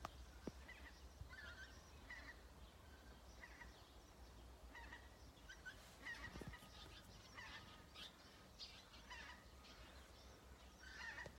Goose Anser sp., Anser sp.
Count100 - 120
StatusPasses over (transit)